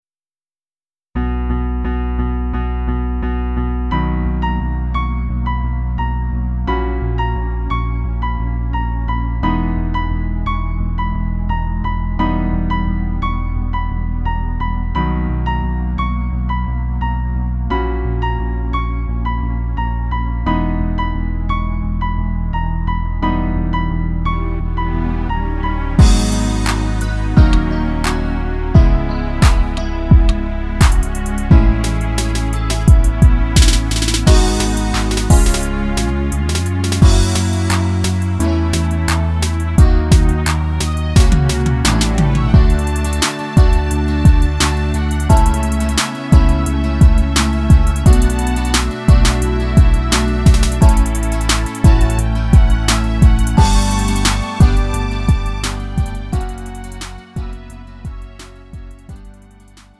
음정 원키 3:57
장르 가요 구분 Lite MR